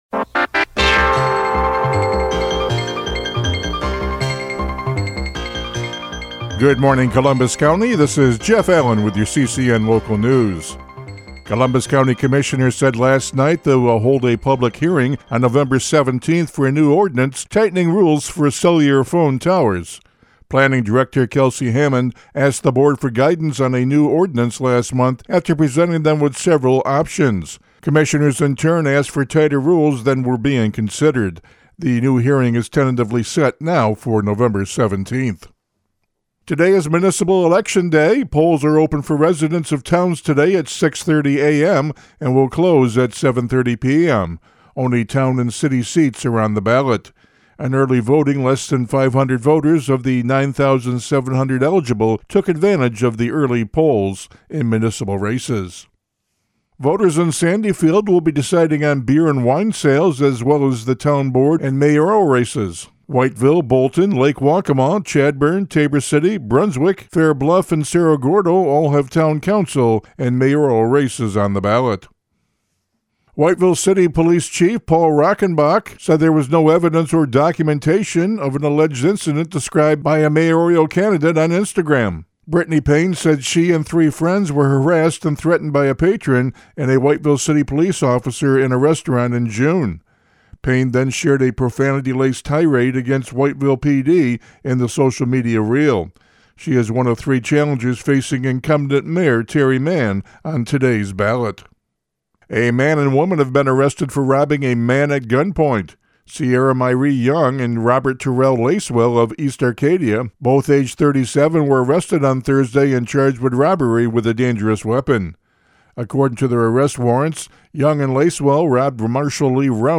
CCN Radio News — Morning Report for November 4, 2025